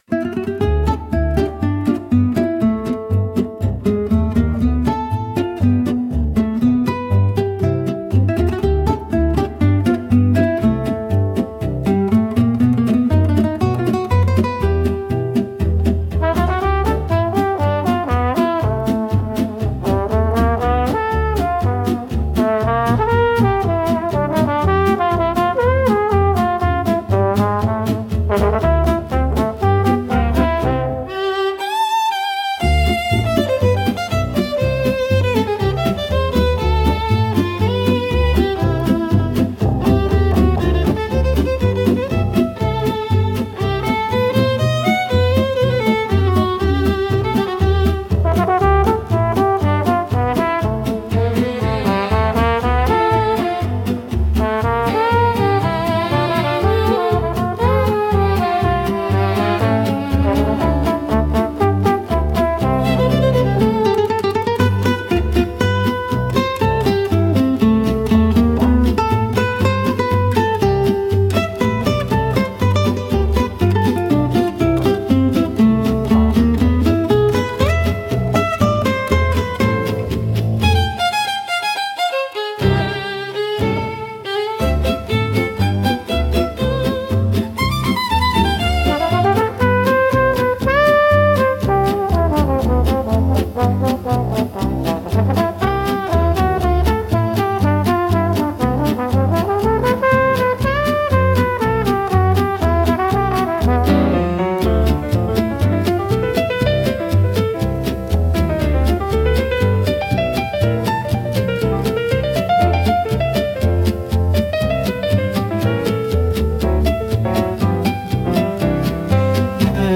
música e arranjo: IA) INSTRUMENTAL 7